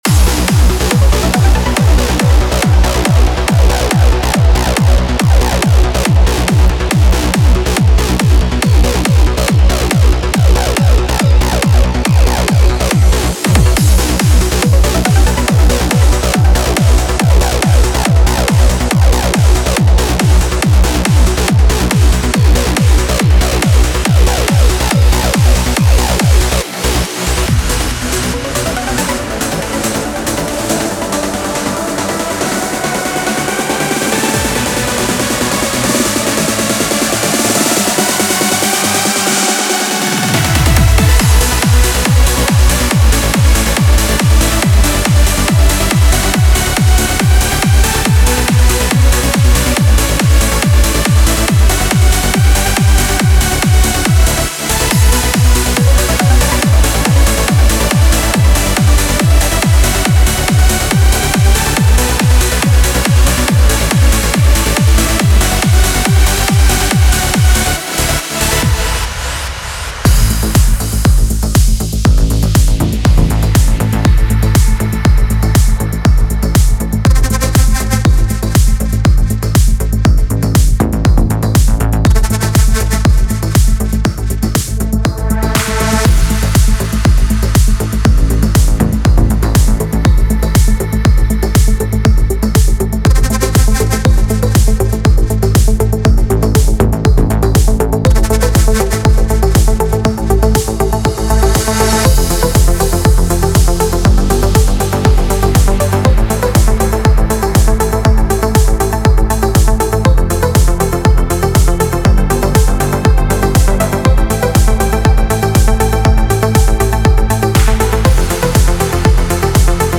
Type: Serum
Trance
Step into the world of euphoric melodies, soaring leads, and driving basslines with Trance State.
Inside, you’ll find 70 meticulously designed presets, covering everything from emotional pads and lush plucks to punchy basses and powerful leads.